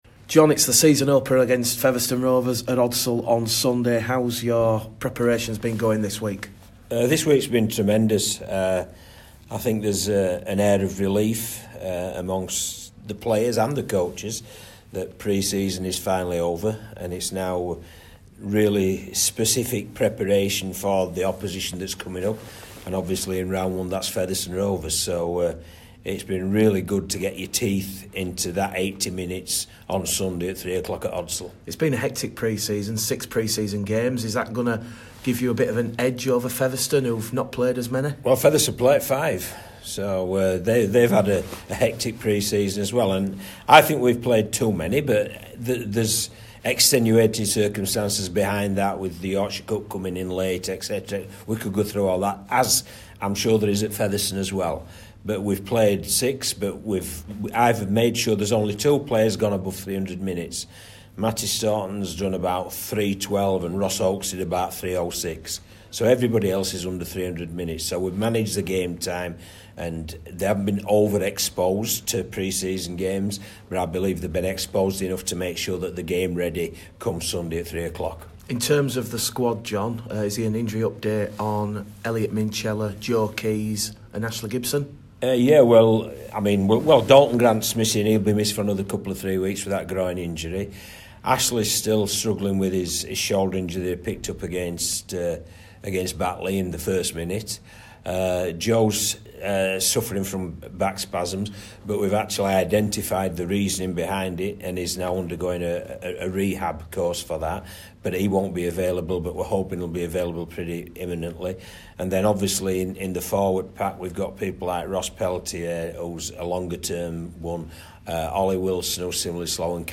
John Kear - Pre Match Interview vs Featherstone